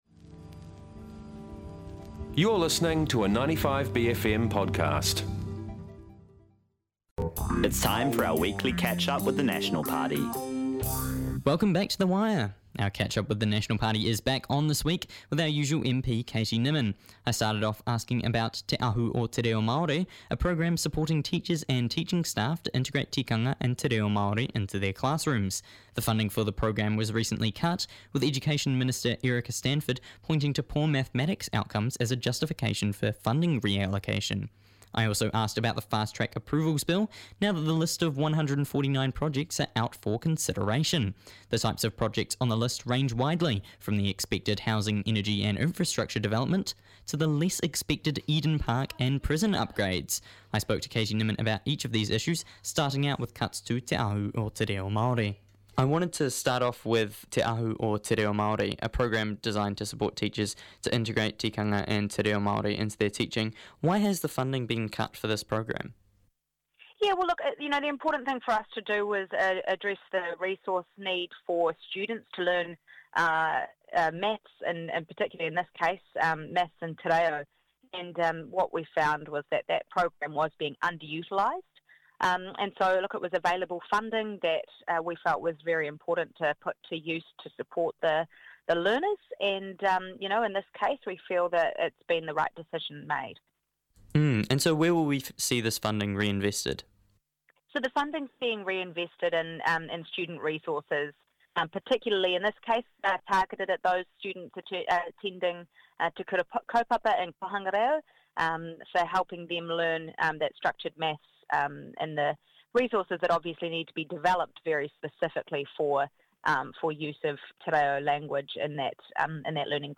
Our weekly catch-up with National Party MP, Carl Bates, every Tuesday on the Wire.